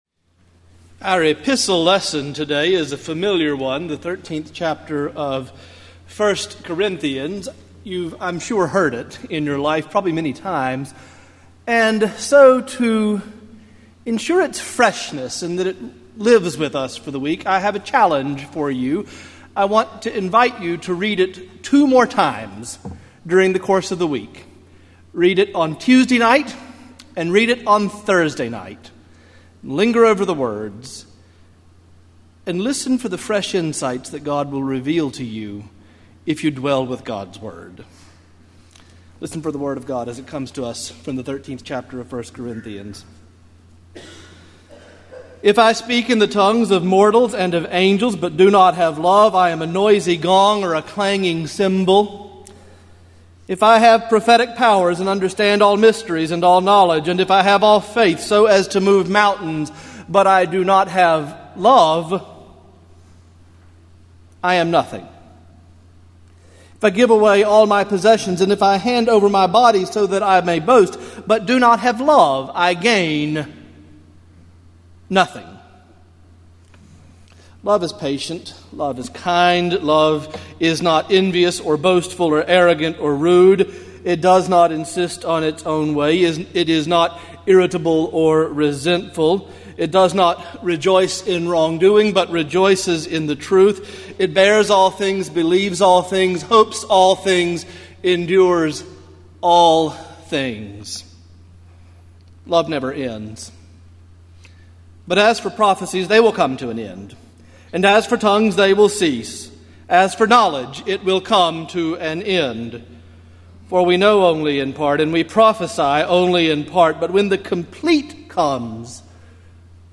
Morningside Presbyterian Church - Atlanta, GA: Sermons: True Love